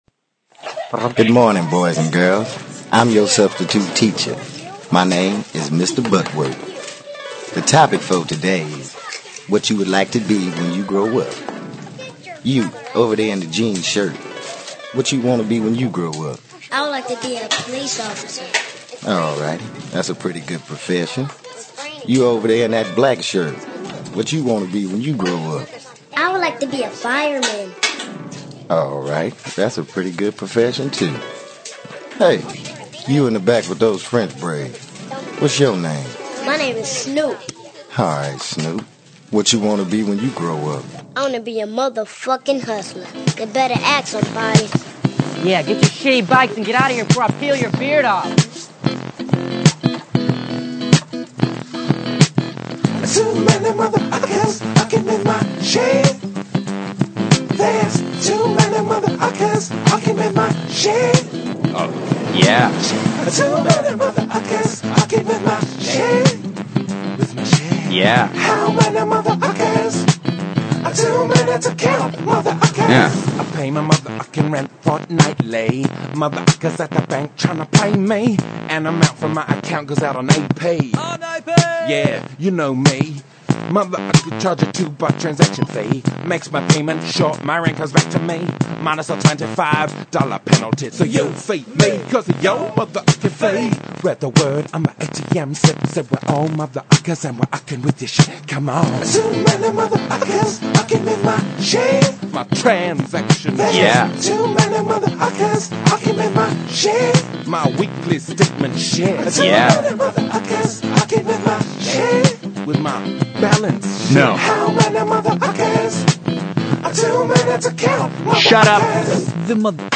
This is the show we did before Thanksgiving and we’re thankful it wasn’t lost.